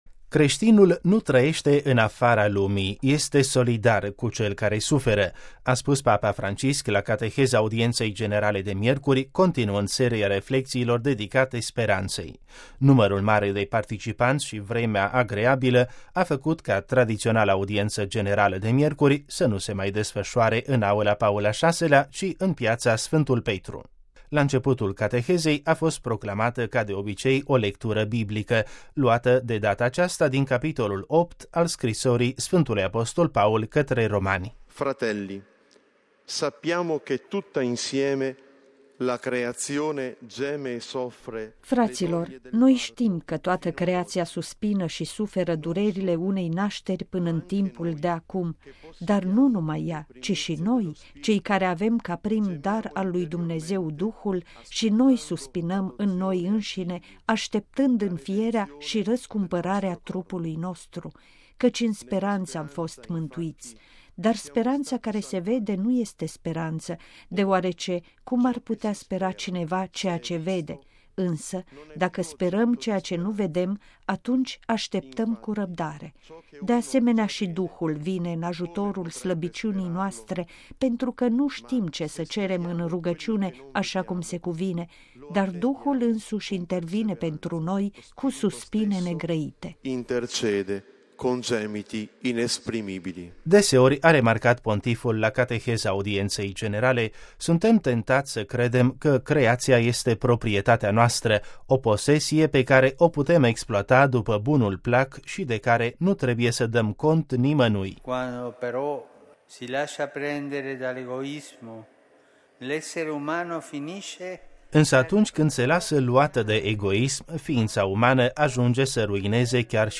RV 22 feb 2017. ”Creștinul nu trăiește în afara lumii, este solidar cu cel care suferă”: a spus papa Francisc la cateheza audienței generale de miercuri, continuând seria reflecțiilor dedicate speranței. Numărul mare de participanți și vremea agreabilă a făcut ca tradiționala audiență generală de miercuri să nu se mai desfășoare în aula Paul al VI-lea, ci în Piața Sfântul Petru.